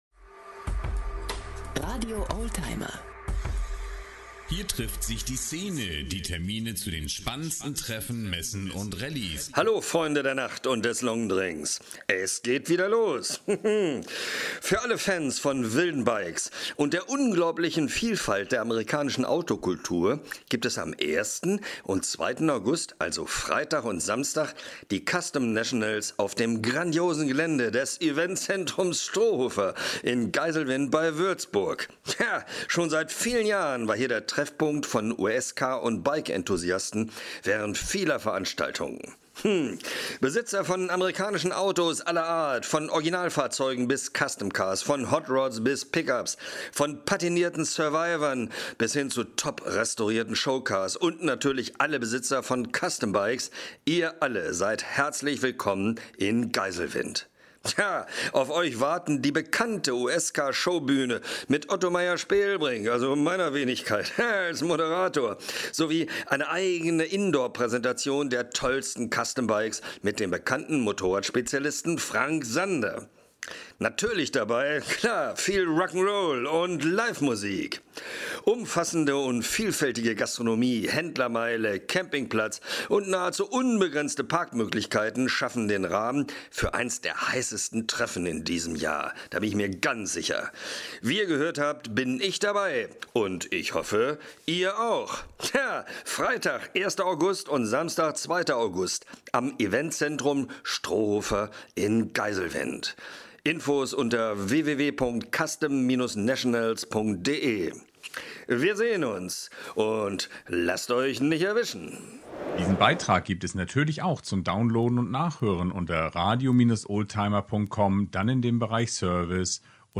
In einem Vorab-Interview informiert Sie RADIO OLDTIMER über ausgewählte Oldtimer-Veranstaltungen.